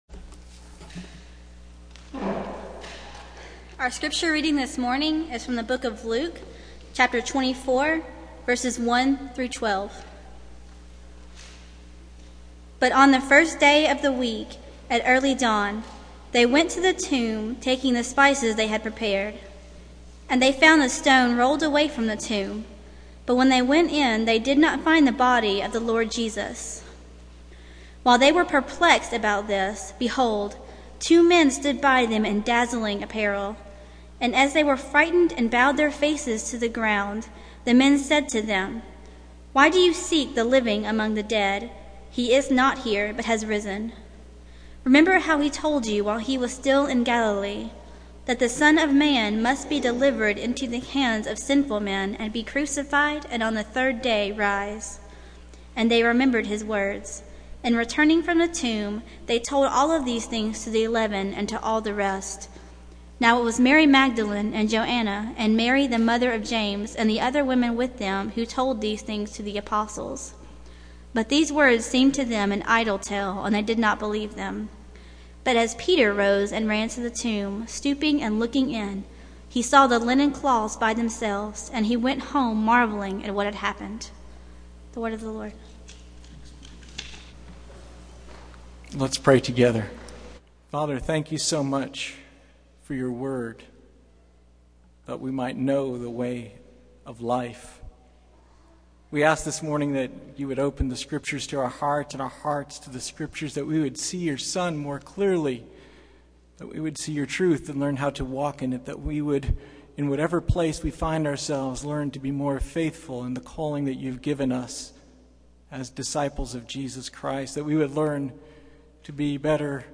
Passage: Luke 24:1-12 Service Type: Sunday Morning